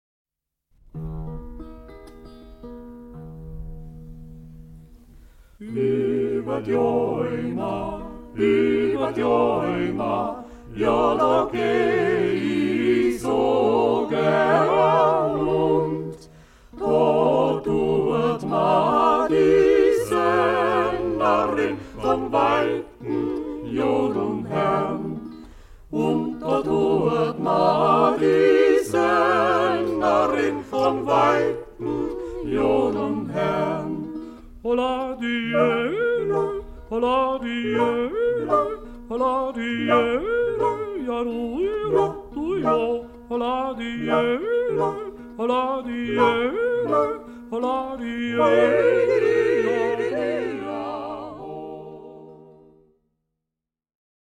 Rundfunkaufnahmen aus Tirol